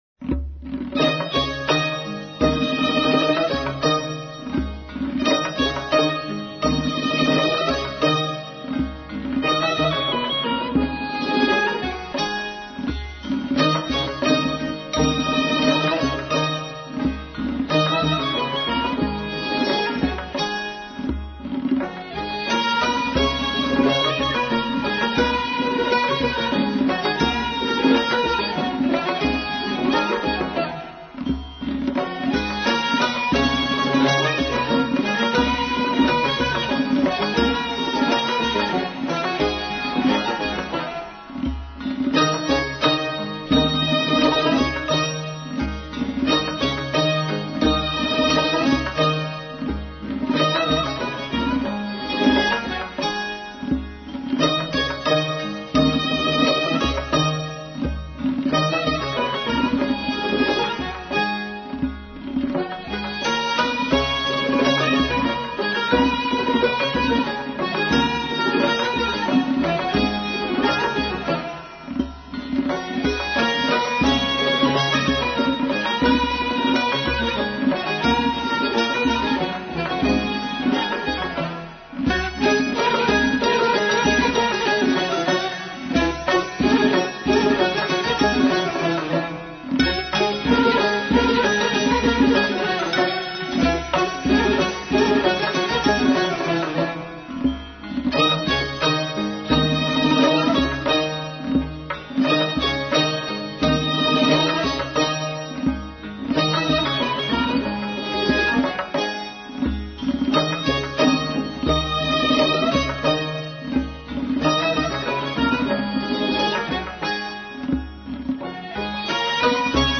یک ریتم سه ضربی
چهار مضراب